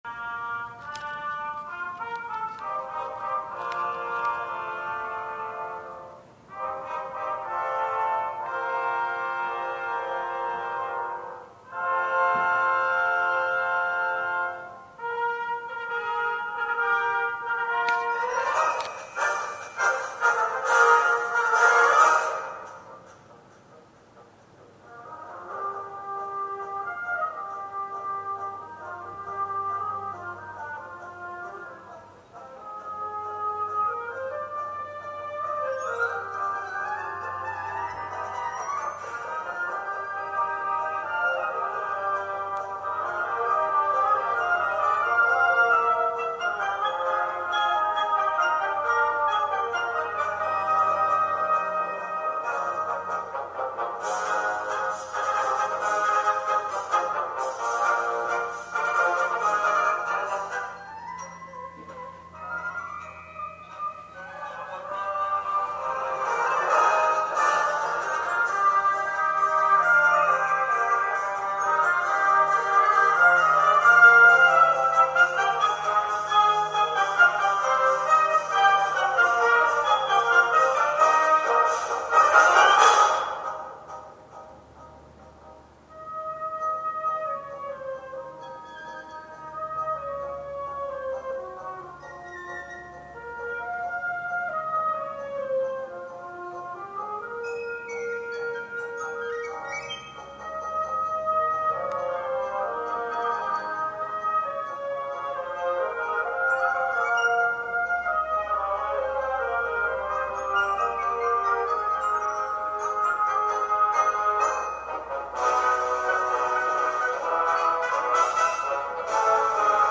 町民音楽祭
現在 聴講生として通っている中学校の吹奏楽と地元の高等学校の吹奏楽は鑑賞した。